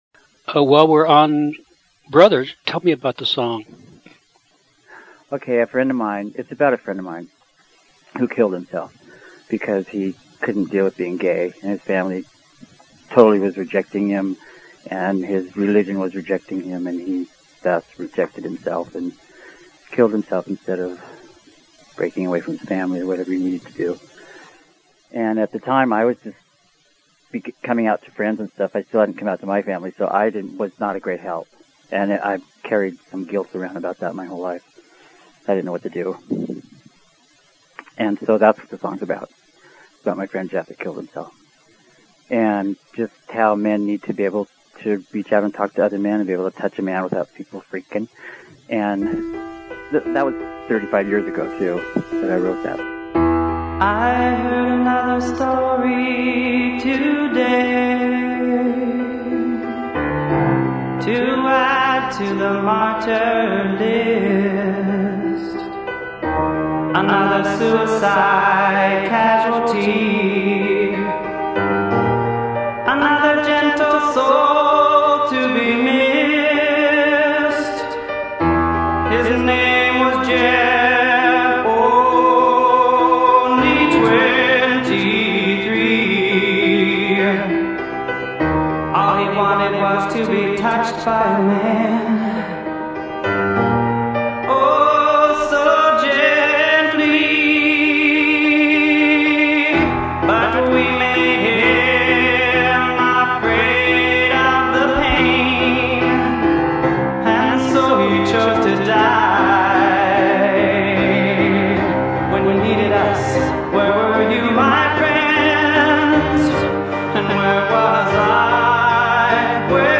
During my interview